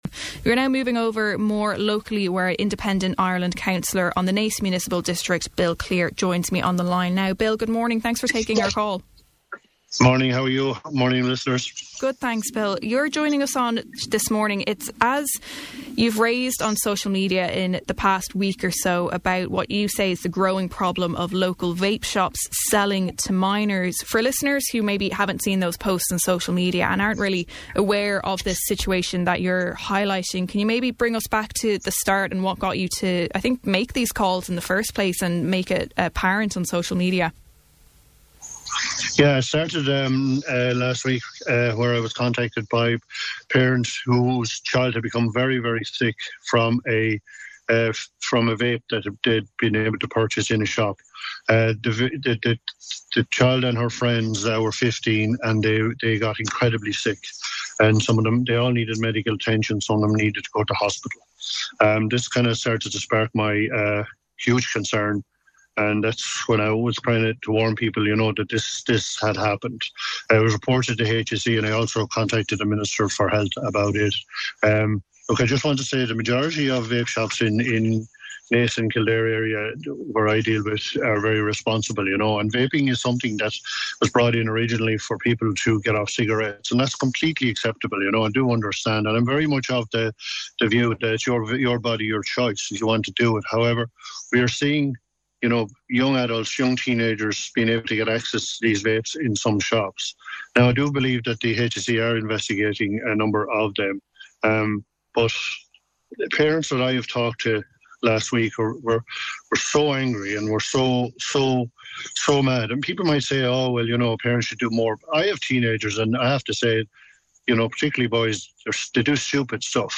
Speaking on Kildare Today, Cllr Clear said that he was contacted by parents whose children had become "incredibly sick" after using a vape.